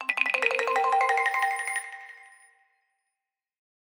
çoğunlukla eğlenceli ve hareketli zil seslerine sahip.